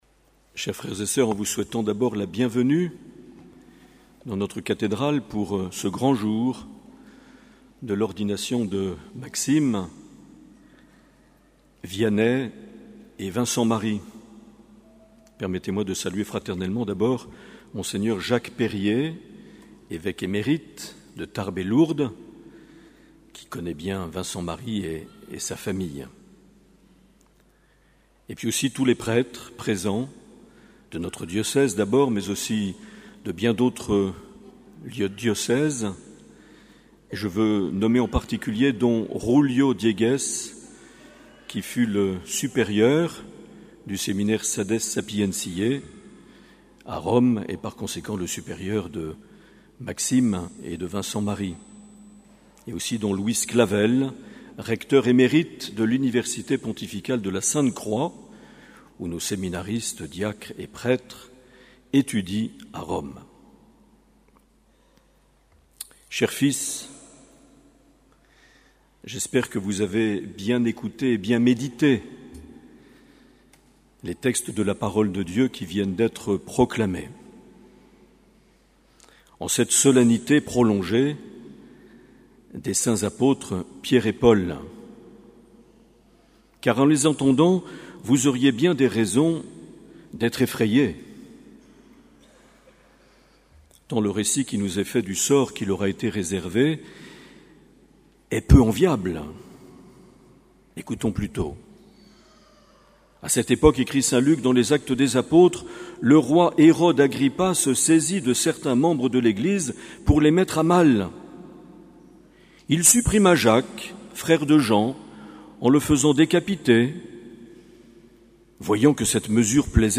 30 juin 2018 - Cathédrale de Bayonne - Ordinations sacerdotales
Les Homélies
Une émission présentée par Monseigneur Marc Aillet